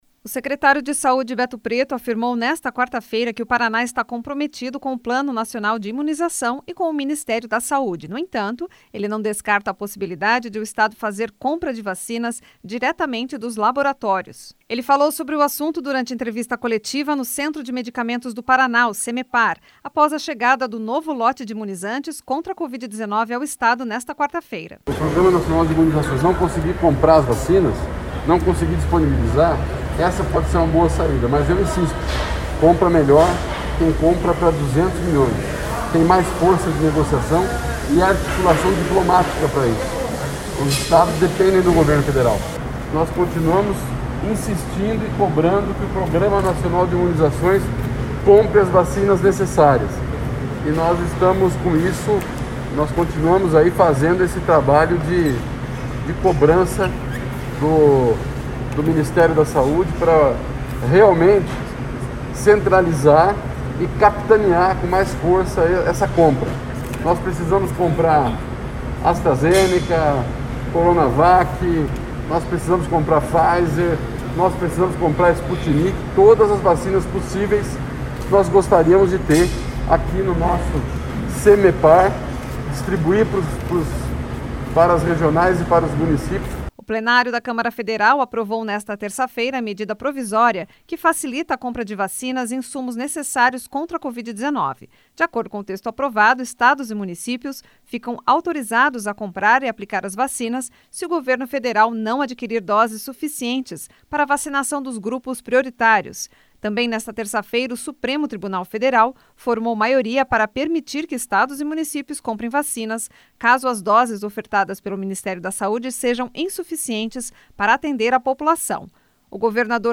No entanto, ele não descarta a possibilidade de o Estado fazer a compra de vacinas diretamente dos laboratórios. Ele falou sobre o assunto durante entrevista coletiva no Centro de Medicamentos do Paraná (Cemepar), após a chegada do novo lote de imunizantes contra covid-19 ao Estado nesta quarta-feira.
Repórter